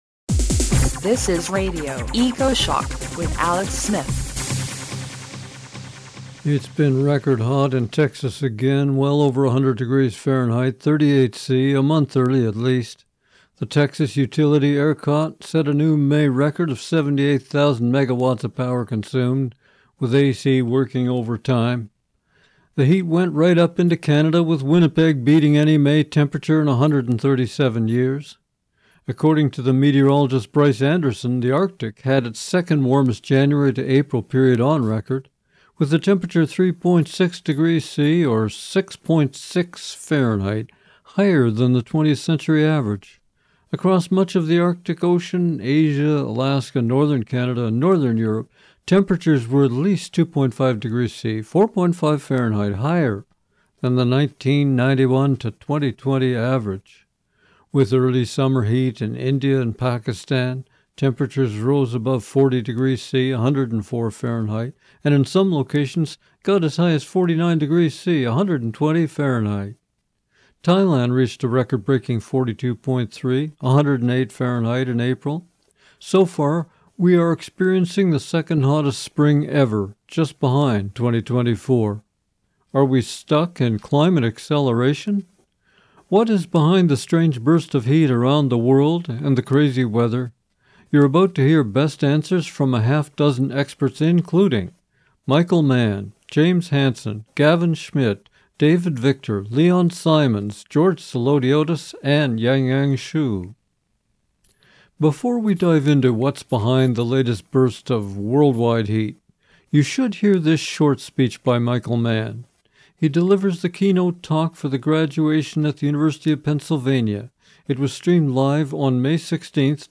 THE LATEST FROM MICHAEL MANN Before we dive into what’s behind the latest burst of world-wide heat, you should hear this short speech by Michael Mann. He delivers the keynote talk for Graduation at the University of Pennsylvania.